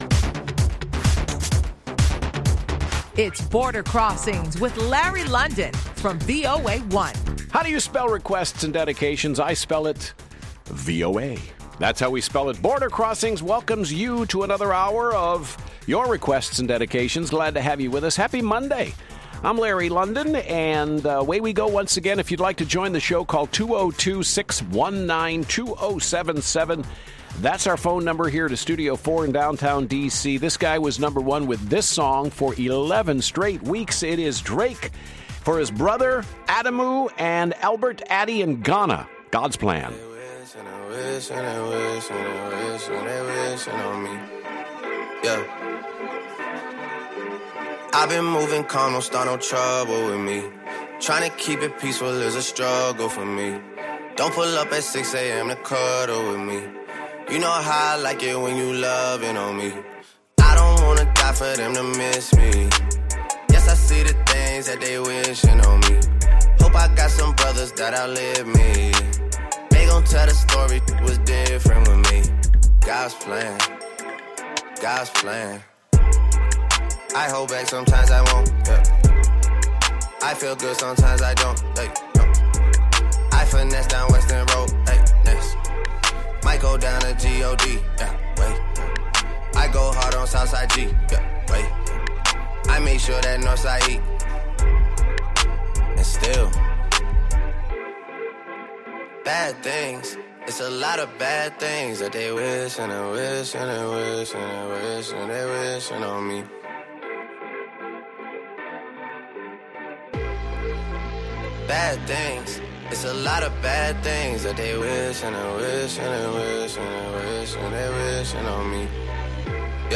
VOA’s live worldwide international music request show